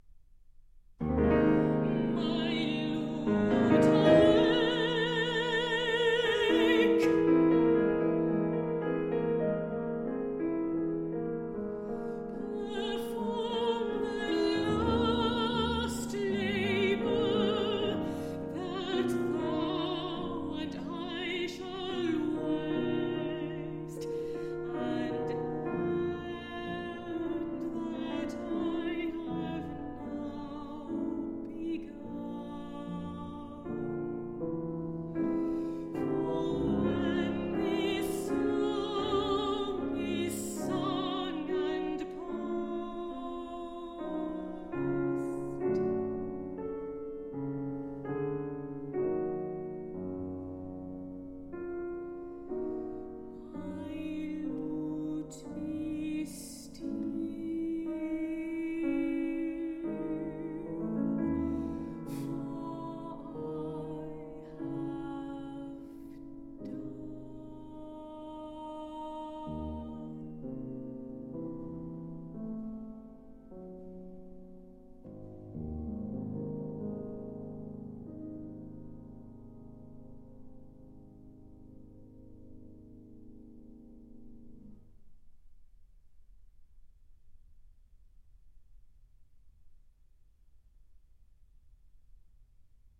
Songs for high voice and pianoforte.
These songs can be sung by a baritone or mezzo-soprano.